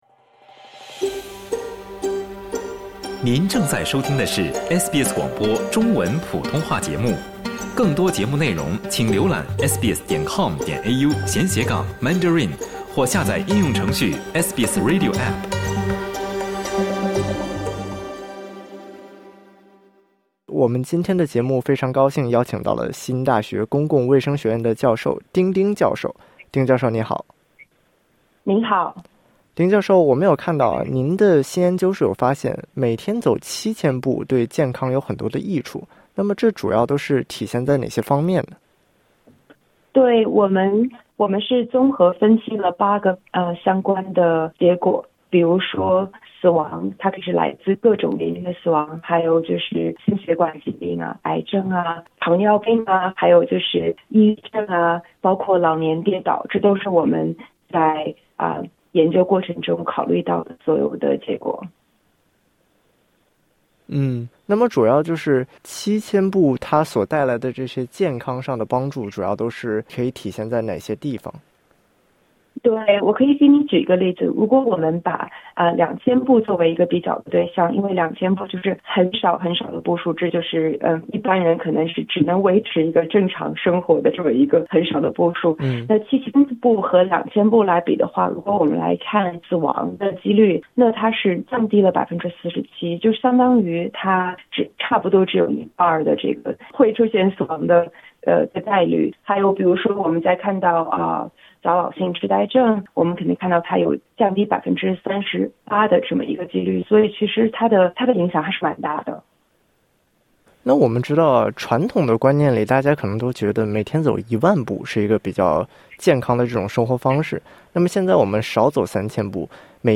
（点击音频，收听完整报道）